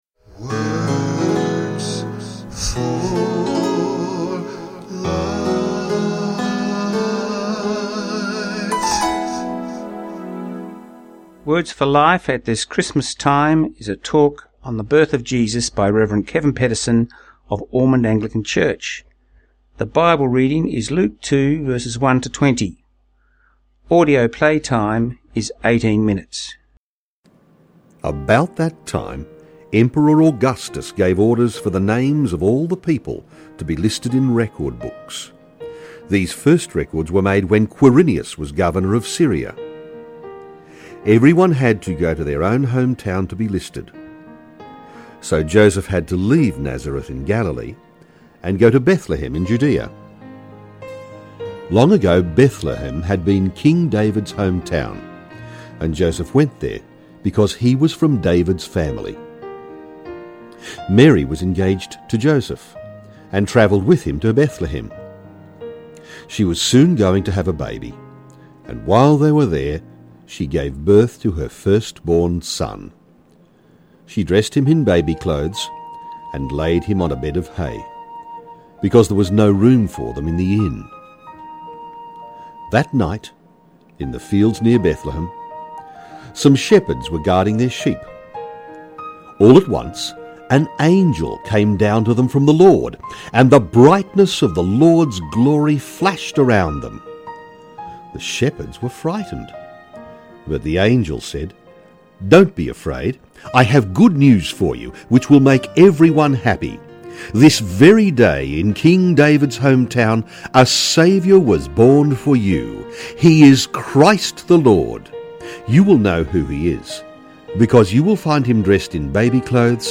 Our Words for Life talk